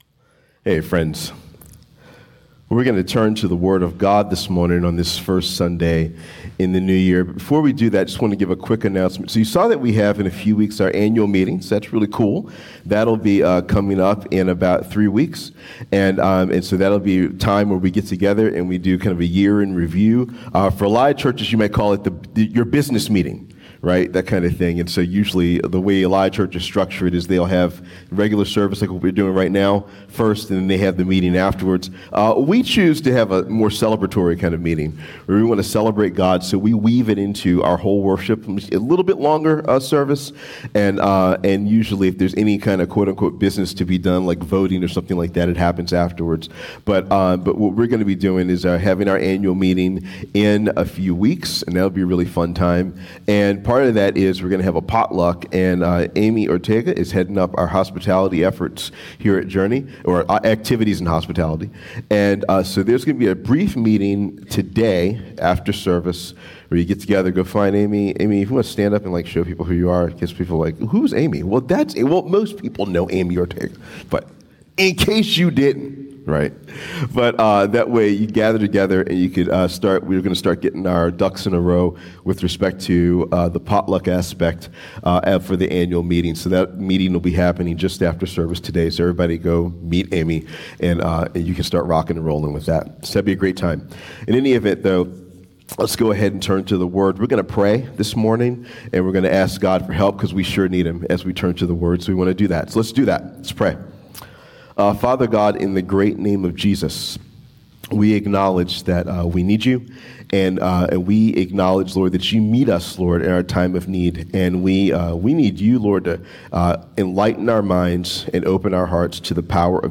Sermons | Journey Community Church